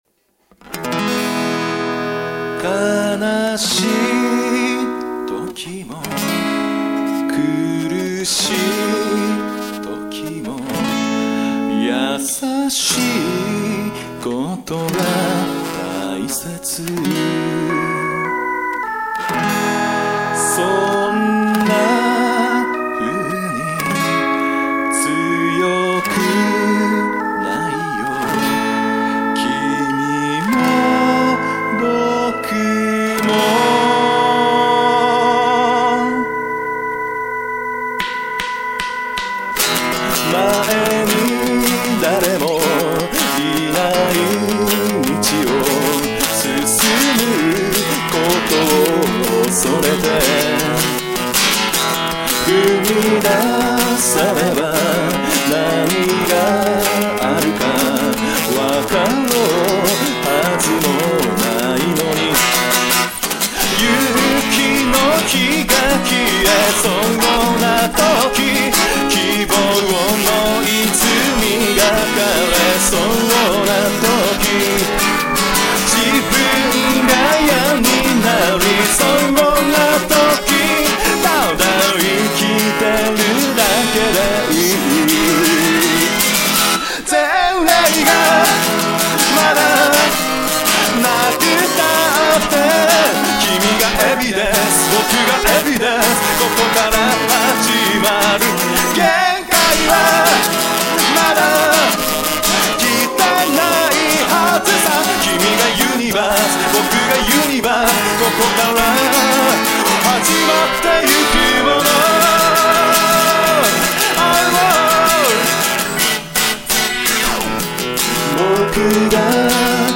エレキギターもドラムもない曲だけど、魂はロックだぜ！